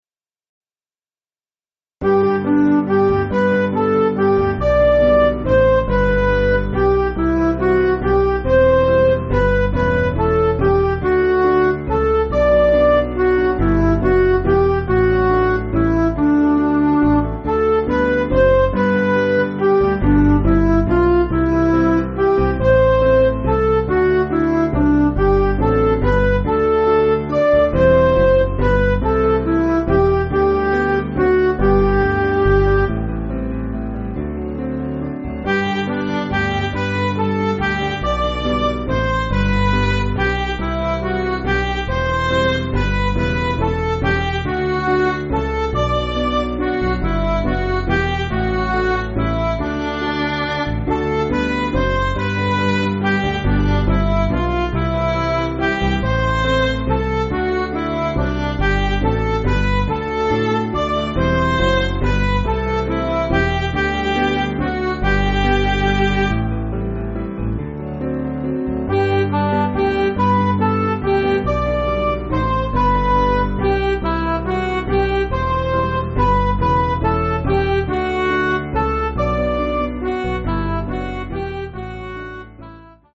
Piano & Instrumental
(CM)   6/G